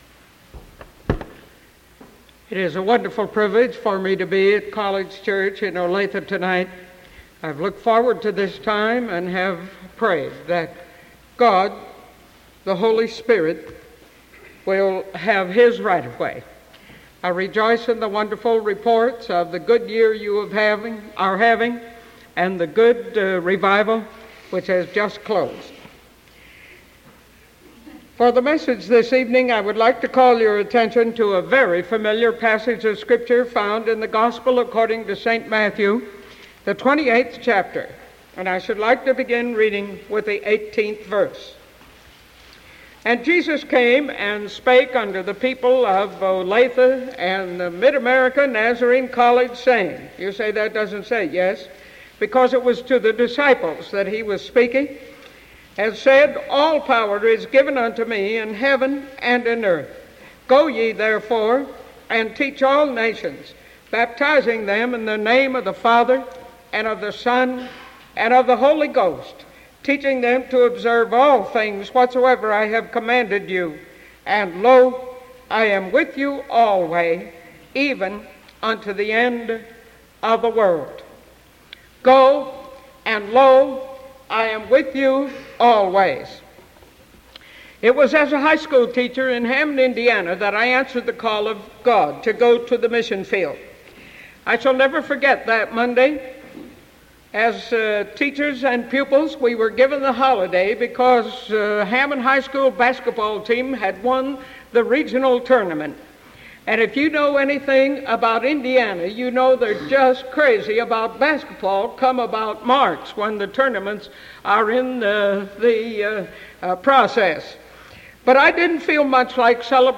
Sermon November 9th 1975 PM